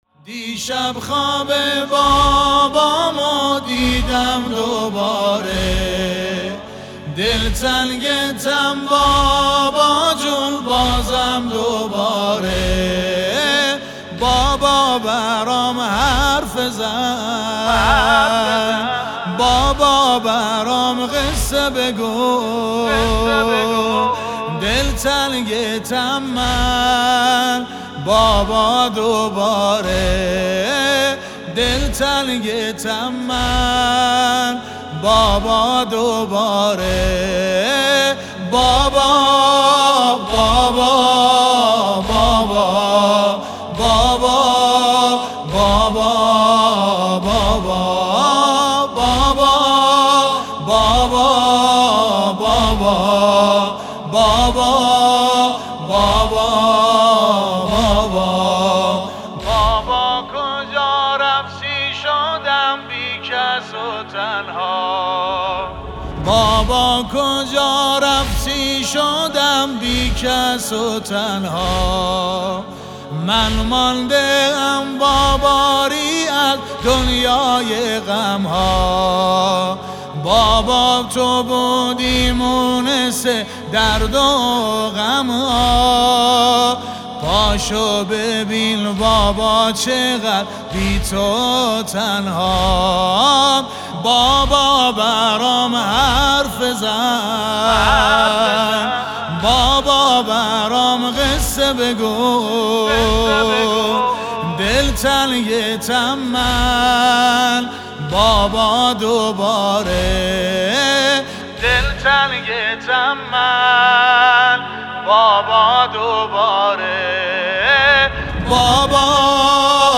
نوحه شیرازی